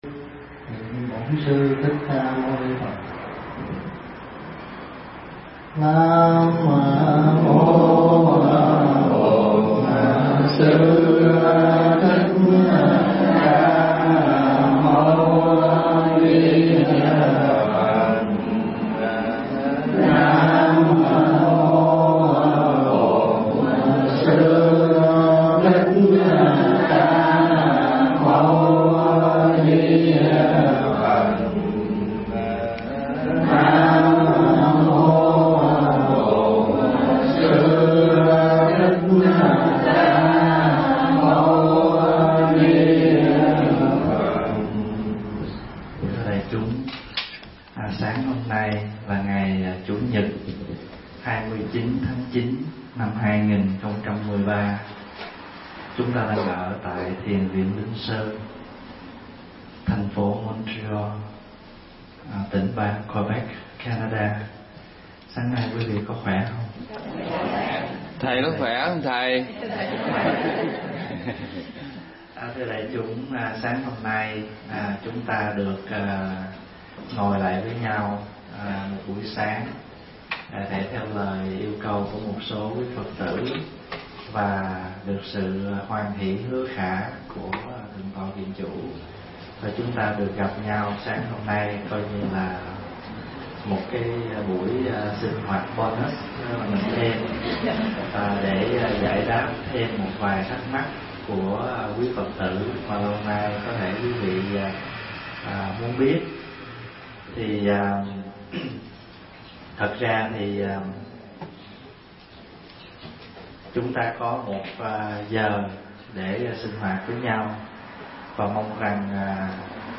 thuyết giảng tại Thiền Viện Minh Sơn, Canada